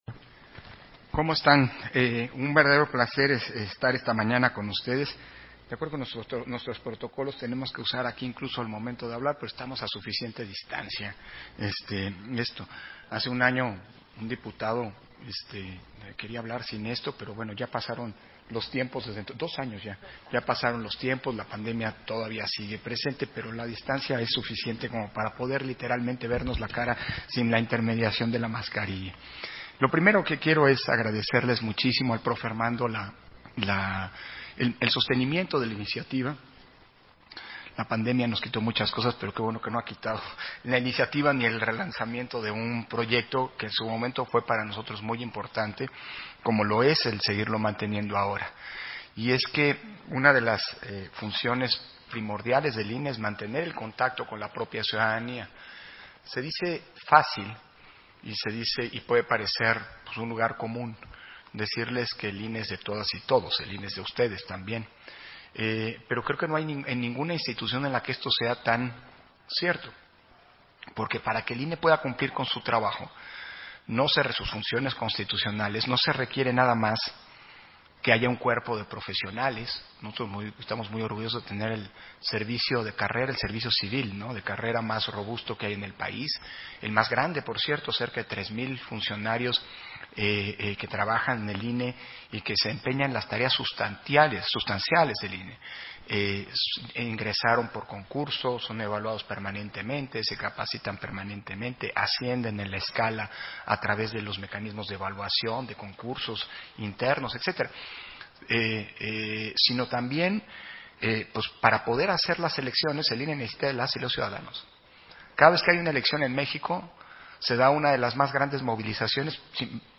Intervención de Lorenzo Córdova, en el encuentro con Dreamers que radican en los Estados Unidos de América